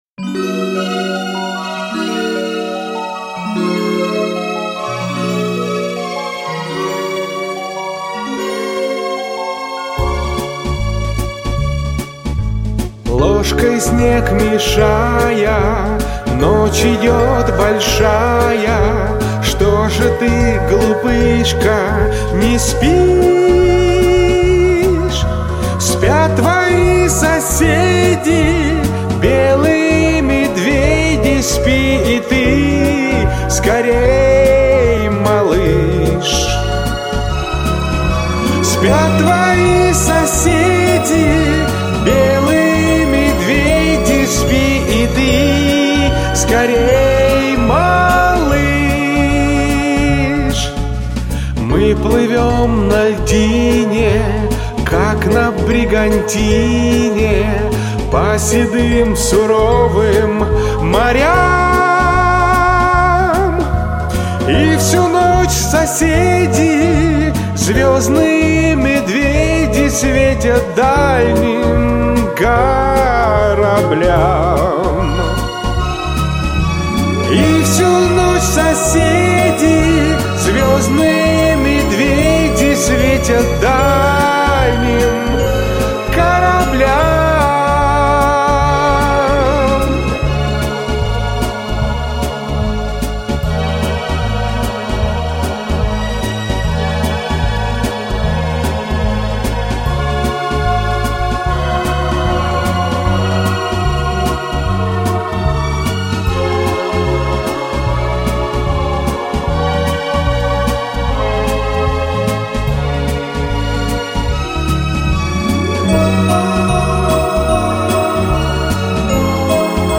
• Качество: Хорошее
🎶 Колыбельные песни / Песни из мультфильмов